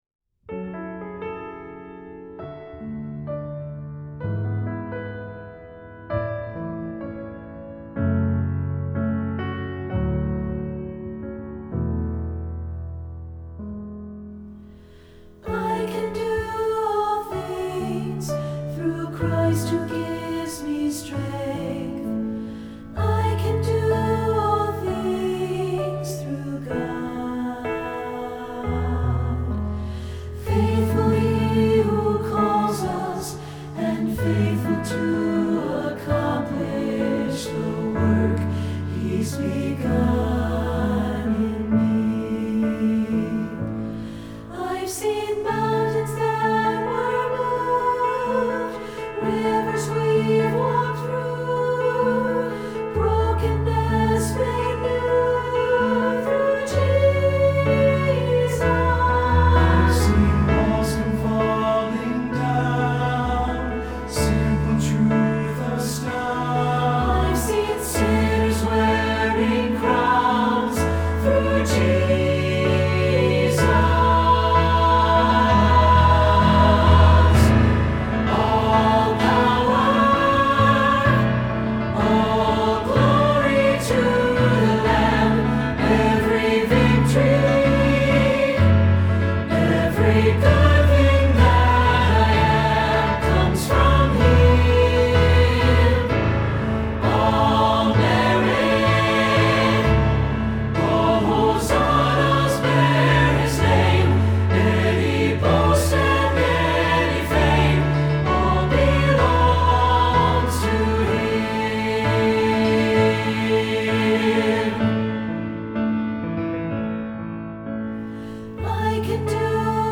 Choral Church
An epic, inspiring anthem of witness!
SATB